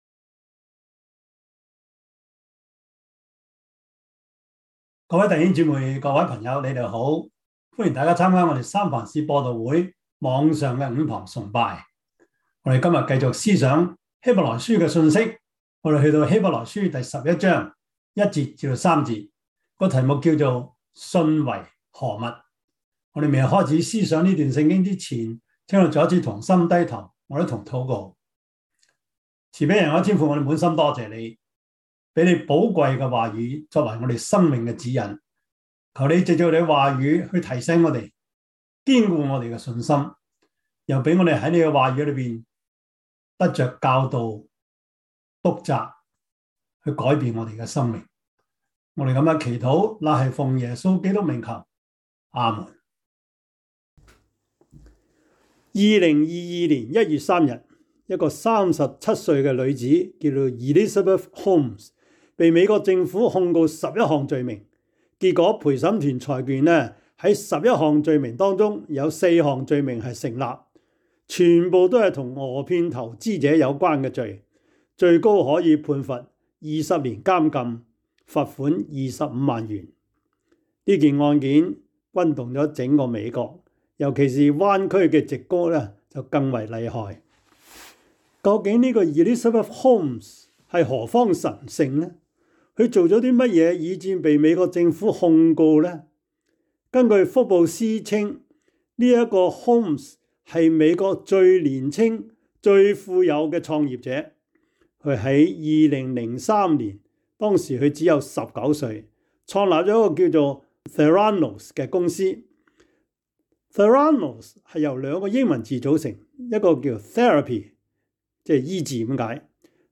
希伯來書 11:1-3 Service Type: 主日崇拜 希 伯 來 書 11:1-3 Chinese Union Version
Topics: 主日證道 « 美好的前景 第二十一課: 非基督教運動(1) »